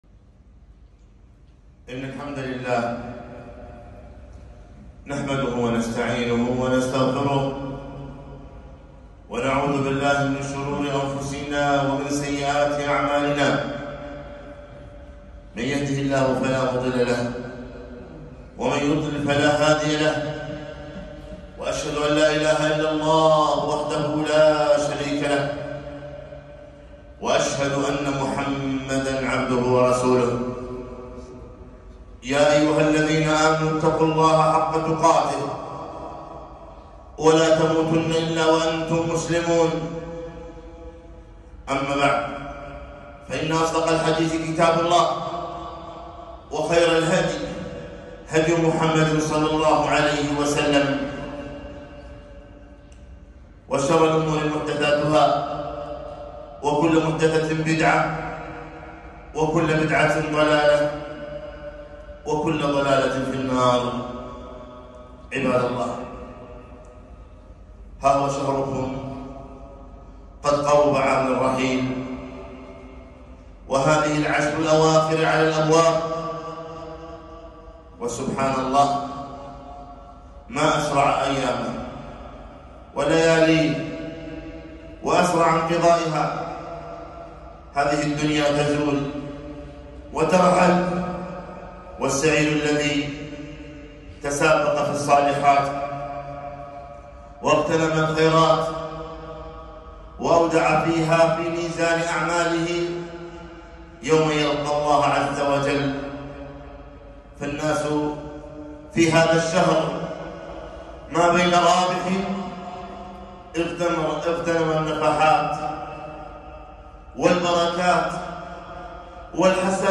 خطبة - لا تخسروا ليلة القدر